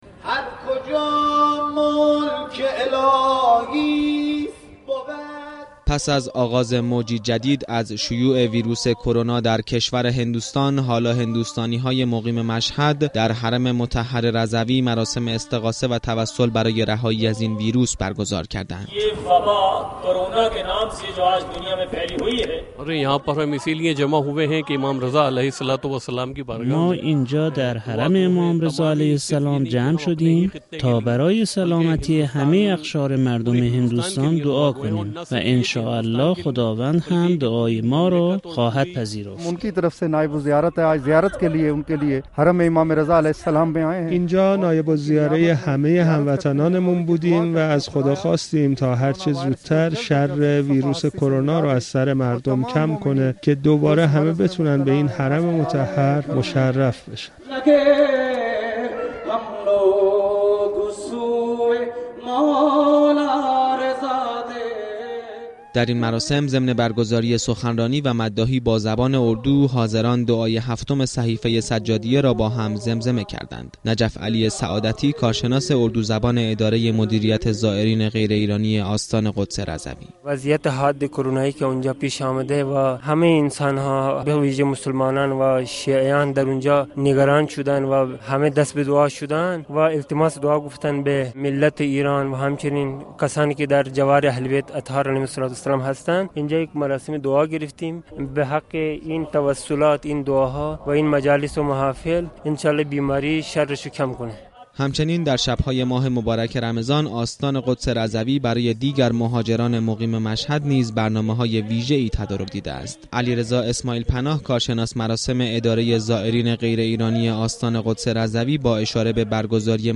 مراسم استغاثه و توسل برای رهایی از ویروس كرونا با حضور شیعیان هند و پاكستان مقیم ایران در حرم مطهر رضوی برگزار شد.